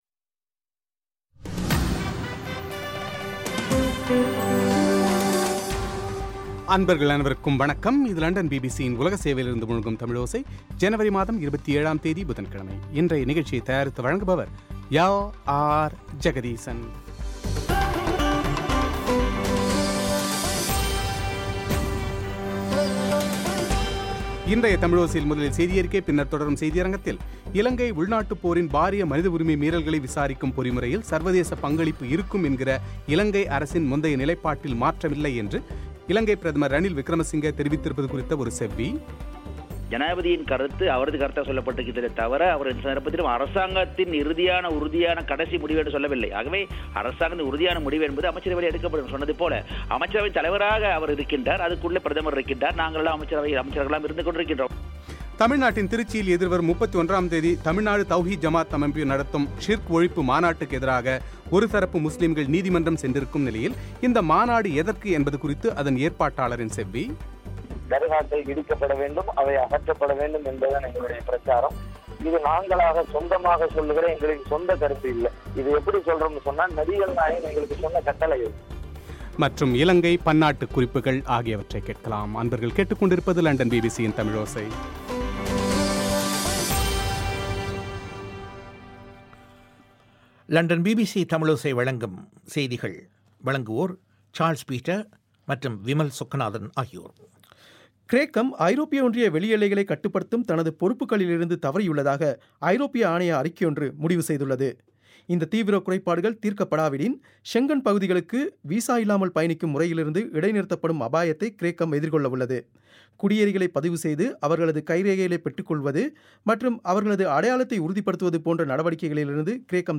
இலங்கை உள்நாட்டுப் போரின் பாரிய மனித உரிமை மீறல்களை விசாரிக்கும் பொறிமுறையில் சர்வதேச பங்களிப்பு இருக்கும் என்கிற இலங்கை அரசில் நிலைப்பாட்டில் மாற்றமில்லை என்று பிரதமர் ரணில் தெரிவித்திருப்பது குறித்து தேசிய சகவாழ்வு கலந்துரையாடல் அமைச்சர் மனோ கணேசனின் செவ்வி;